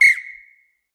whistle.ogg